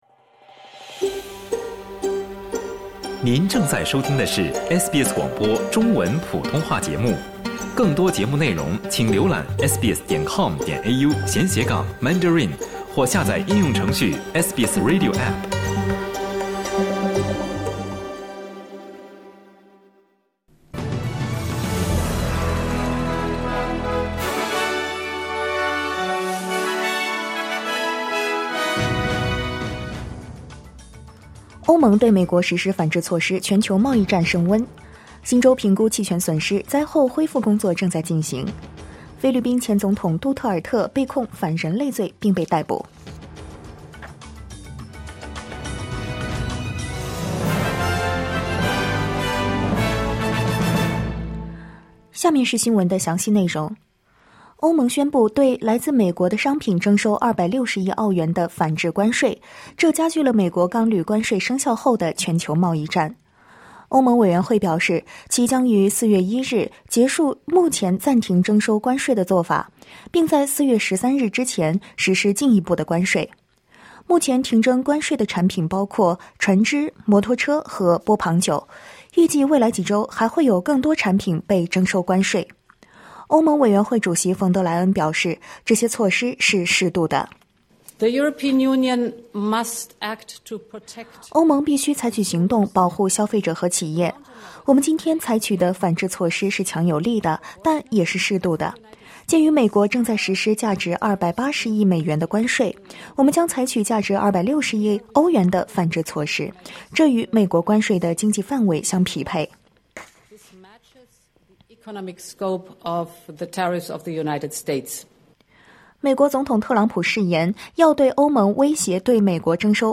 SBS Mandarin morning news.